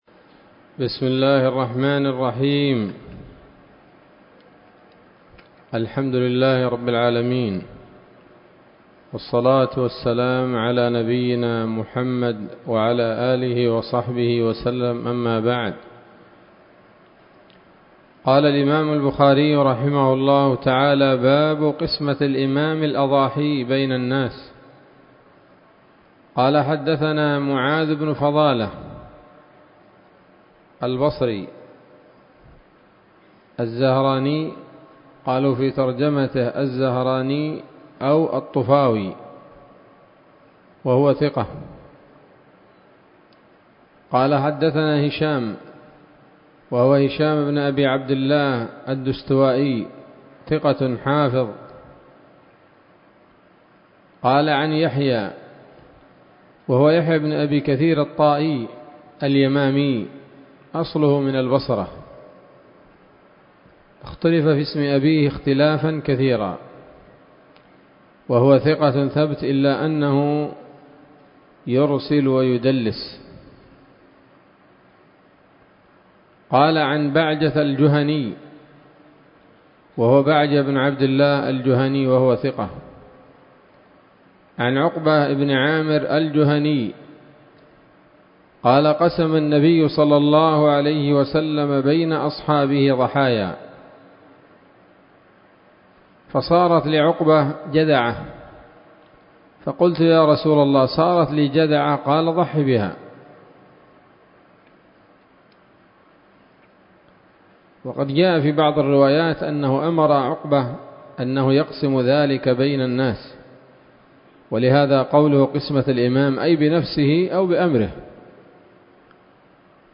الدرس الثاني من كتاب الأضاحي من صحيح الإمام البخاري